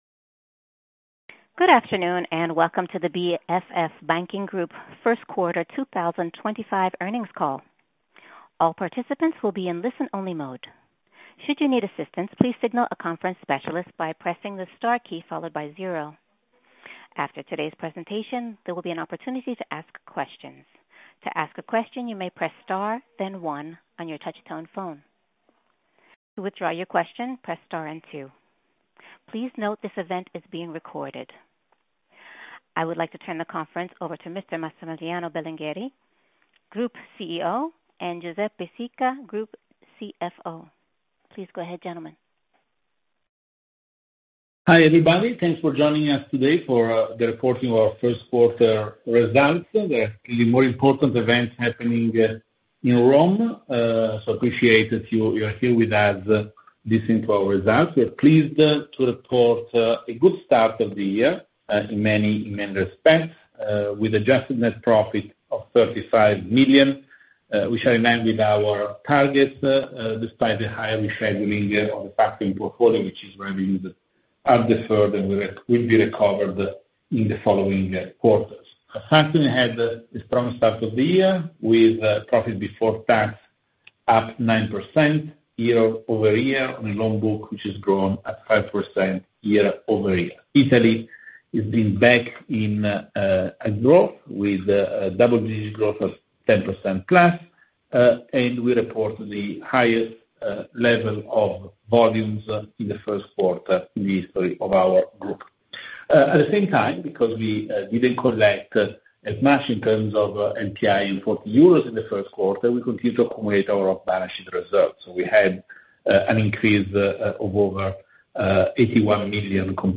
read more Earnings call recording